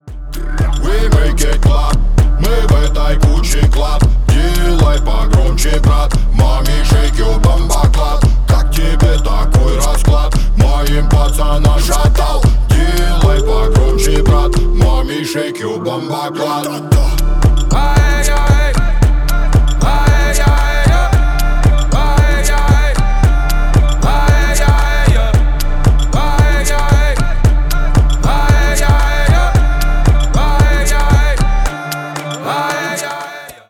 Рэп и Хип Хоп
клубные